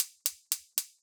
Gas Hob Ignition 02.wav